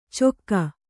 ♪ cokka